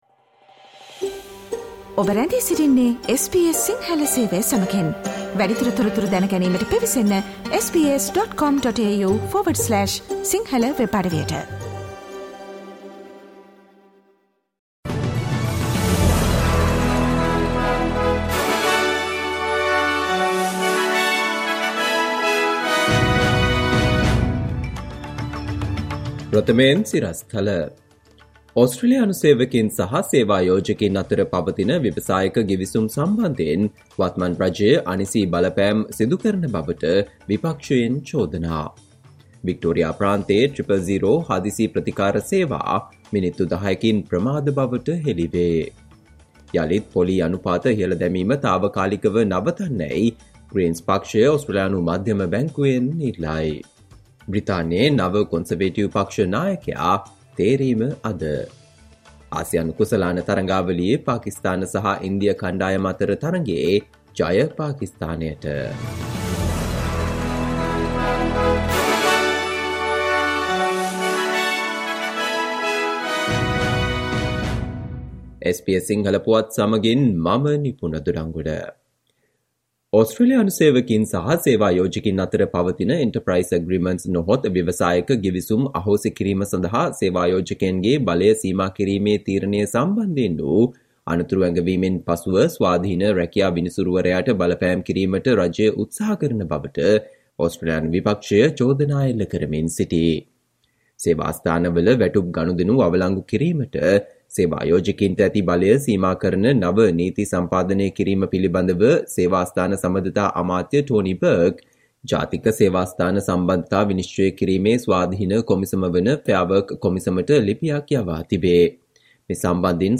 Listen to the SBS Sinhala Radio news bulletin on Monday 05 September 2022